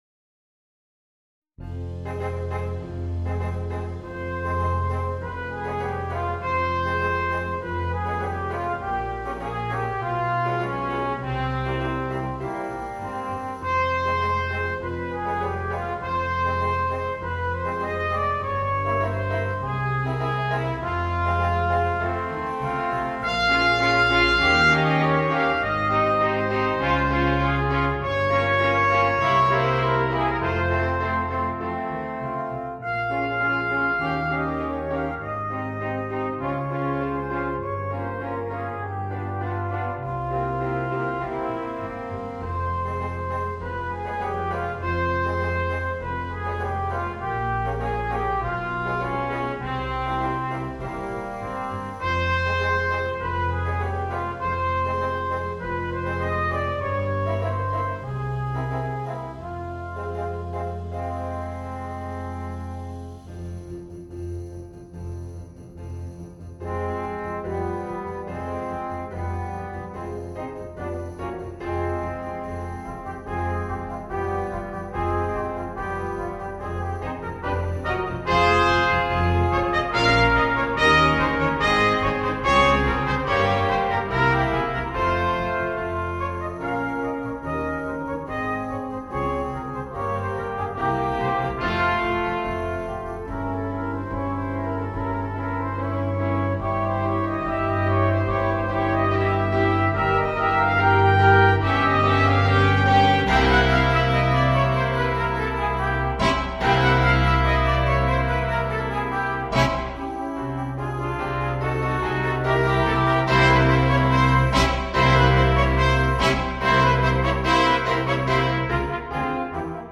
Gattung: Brass Quartet
Besetzung: Ensemblemusik für 4 Blechbläser